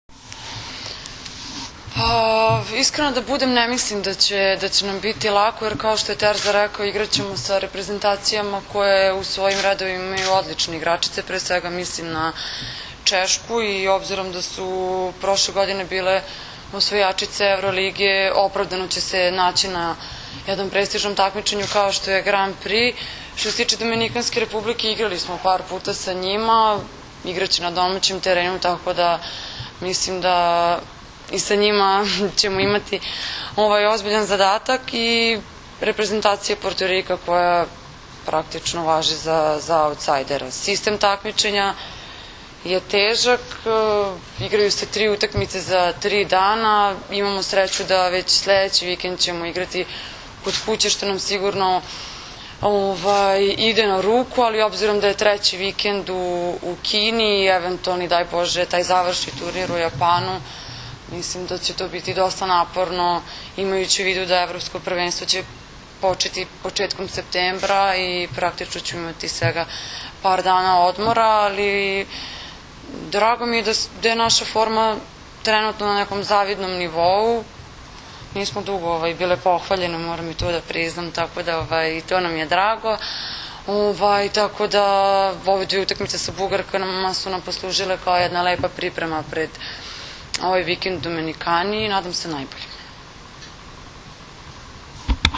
danas je u beogradskom hotelu “M” održana konferencija za novinare kojoj su prisustvovali Zoran Terzić
IZJAVA